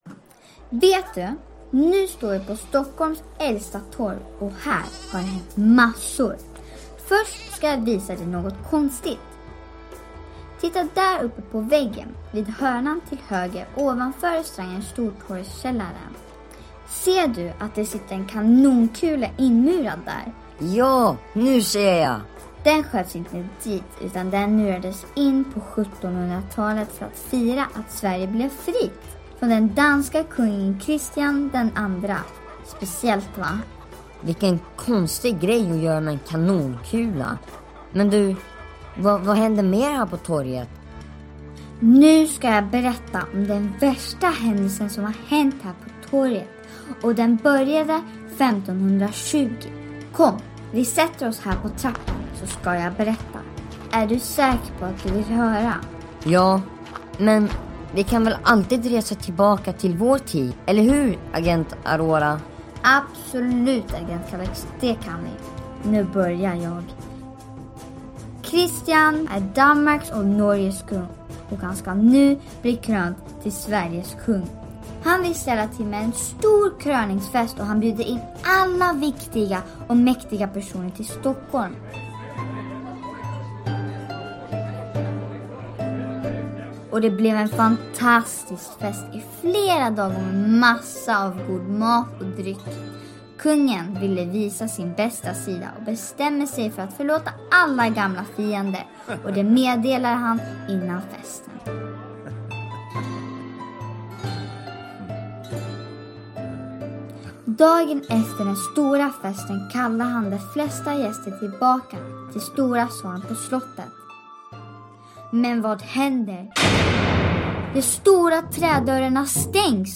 En audioguide påminner om en vanlig guidad tur.